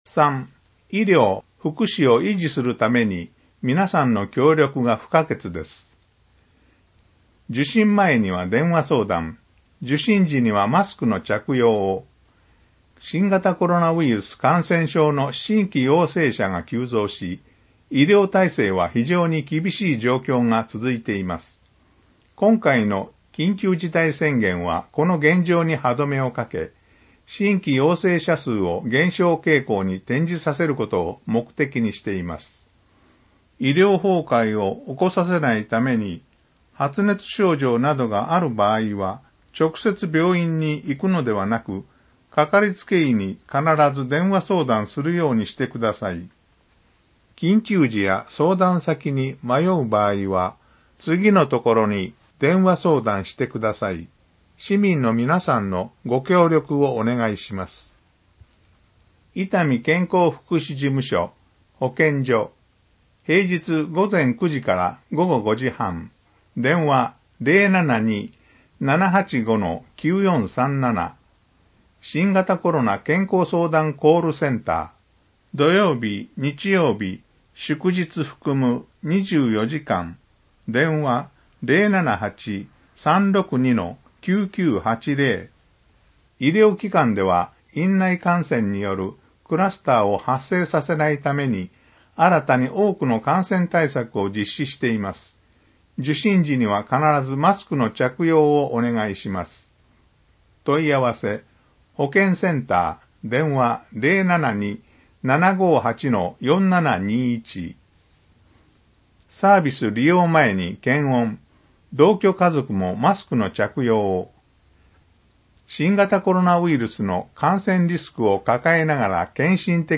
臨時号 声の広報（音訳データ） 声の広報としてCDで送付しているデータを、MP3の音声ファイルで掲載しています。